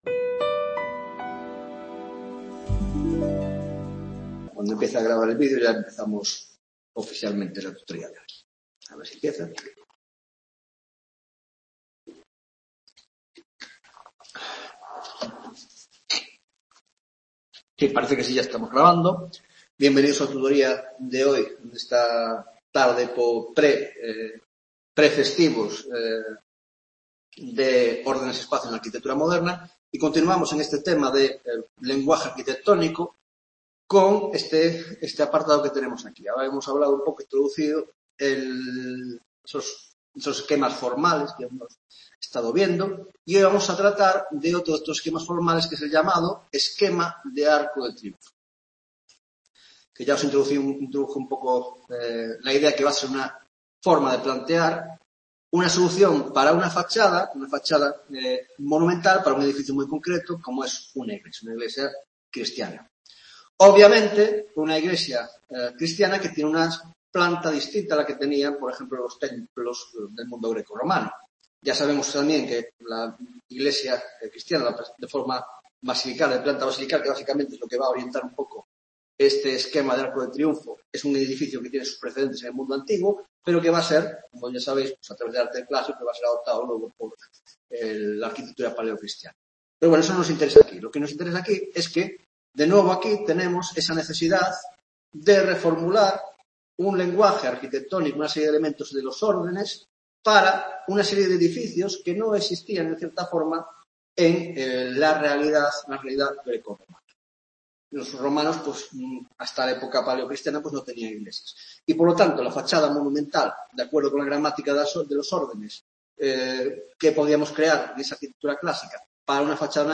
6ª Tutoría